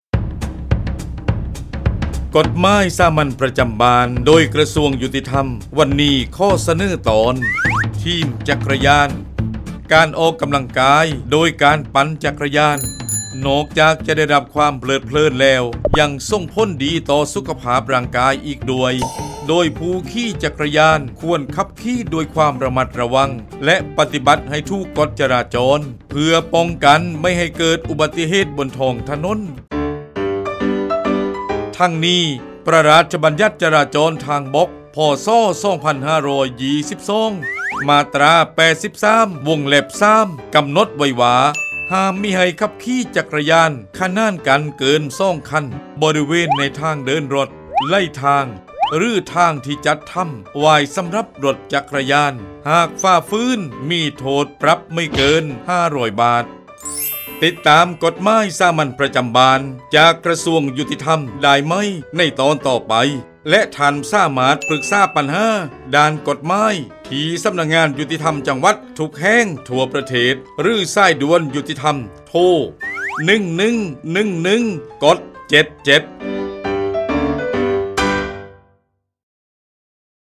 กฎหมายสามัญประจำบ้าน ฉบับภาษาท้องถิ่น ภาคใต้ ตอนทีมจักรยาน
ลักษณะของสื่อ :   คลิปเสียง, บรรยาย